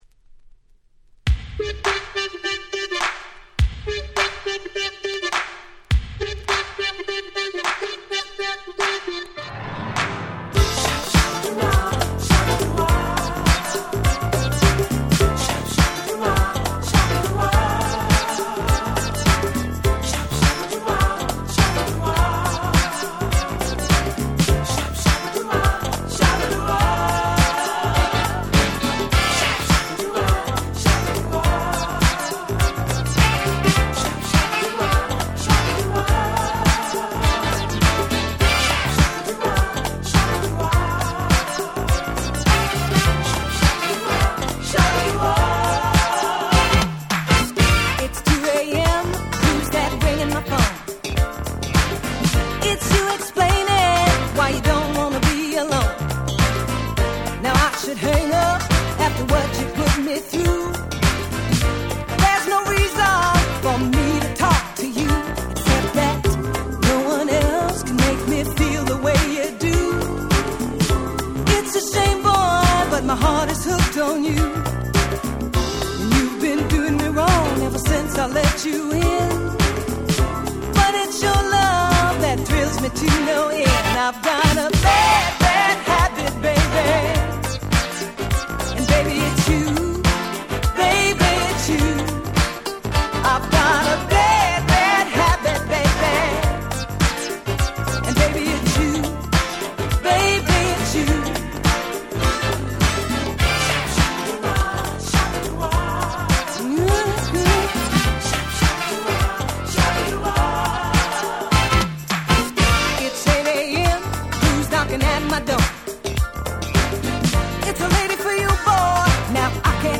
85' 鉄板Dance Classic !!
本当に最高のDance Classicsです！
80's Disco ディスコ ダンクラ ダンスクラシックス